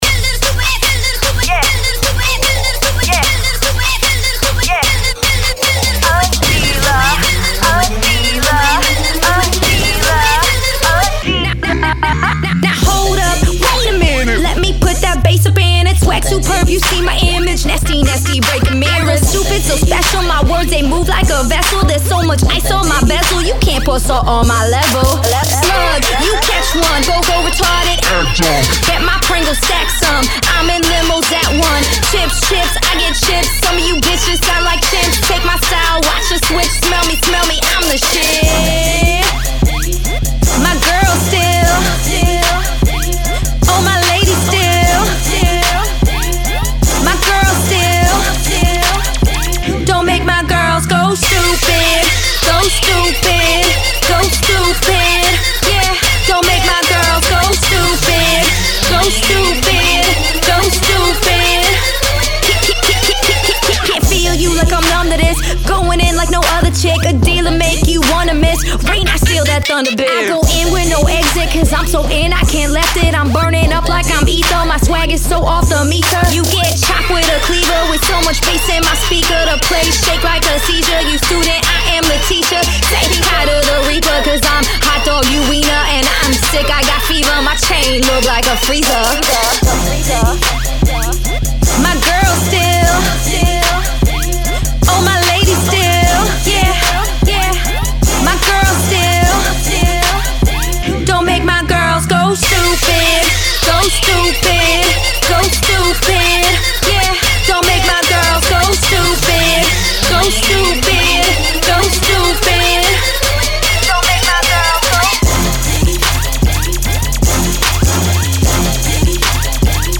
Her freestyle kind of shits on most Emcee’s written lyrics.
(Freestyle)